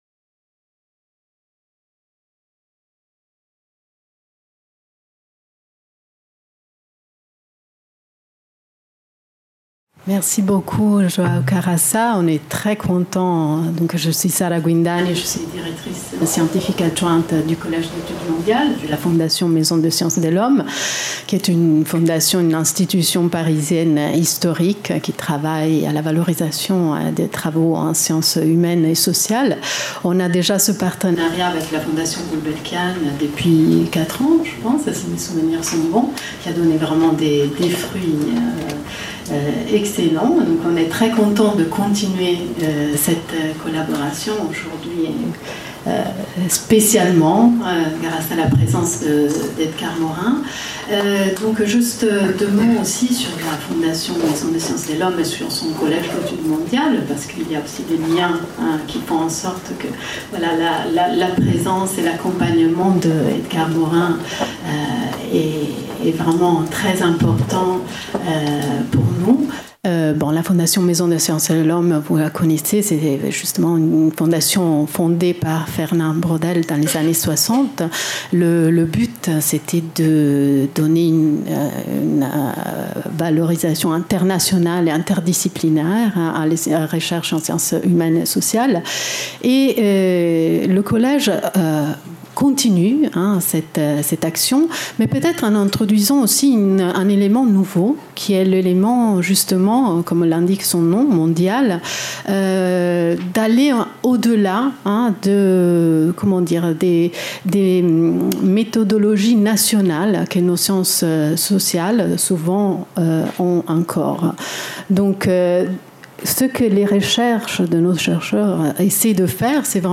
Cycle de conférences Edgar Morin : Globalité et complexité (1/3) Organisé par le Collège d'études mondiales et la Fondation Calouste Gulbenkian.